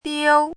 chinese-voice - 汉字语音库
diu1.mp3